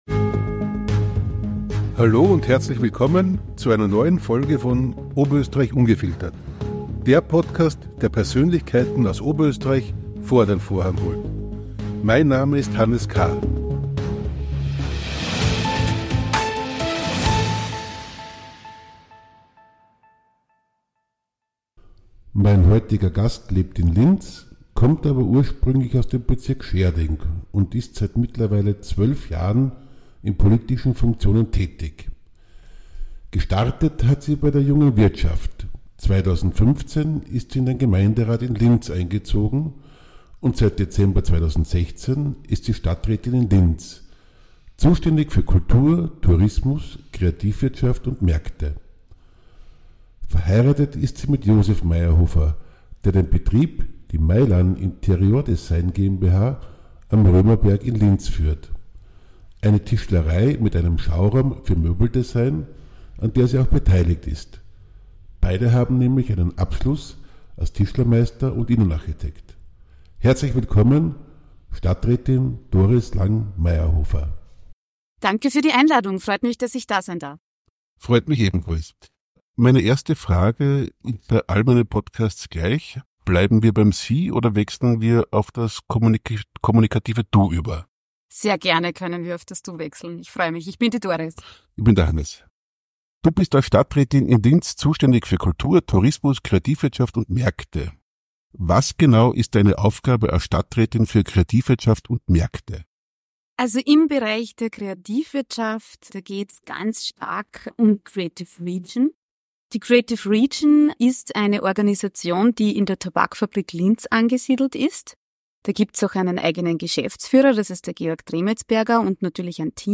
Erleben Sie eine fesselnde Konversation mit Doris Lang Mayerrhofer und entdecken Sie, wie Linz sich zu einer lebendigen Kultur- und Kreativstadt entwickelt hat.